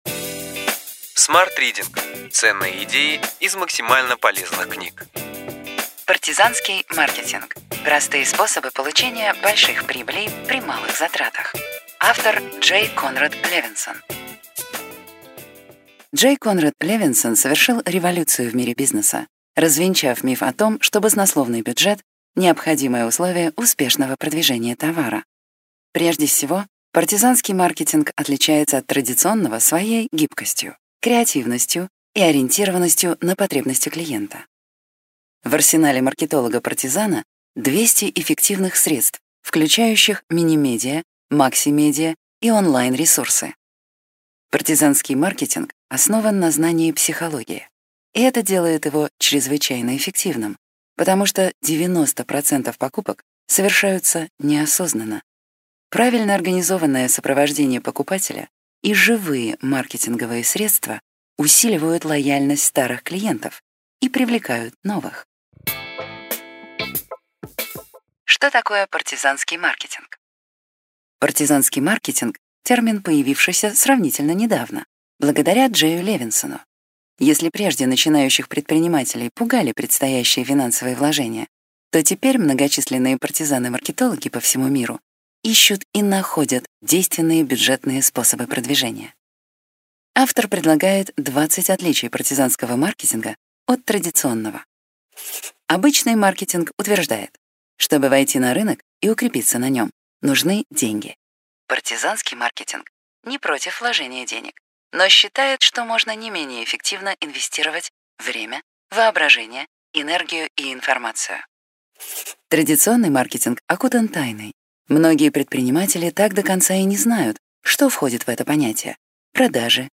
Аудиокнига Ключевые идеи книги: Партизанский маркетинг. Простые способы получения больших прибылей при малых затратах.